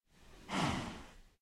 minecraft / sounds / mob / cow / say2.ogg